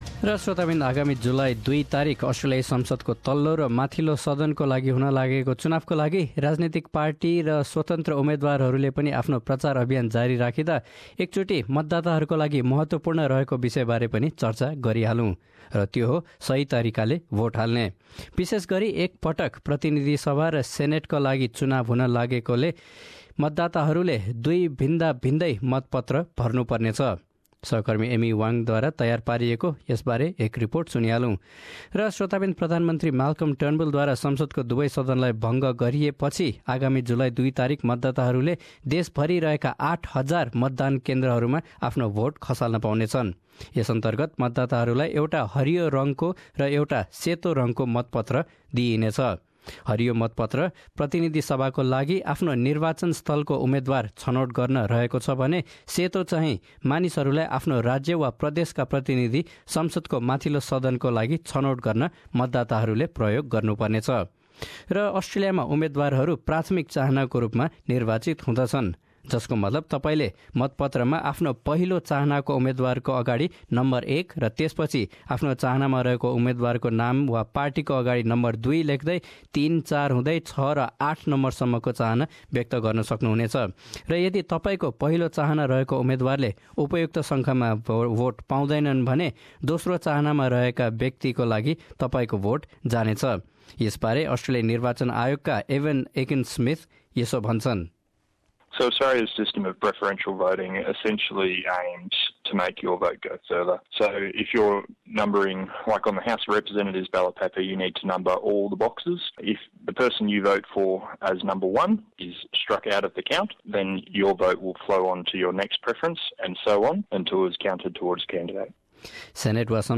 १८ वर्ष भन्दा बढी उमेरका सबैले भोट हाल्न अनिवार्य रहंदा आफ्नो भोट गनिएको निश्चित गर्न तपाईंले के गर्नुपर्ने छ त? प्रस्तुत छ मतदान प्रक्रिया बारे एक रिपोर्ट।